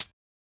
edm-hihat-05.wav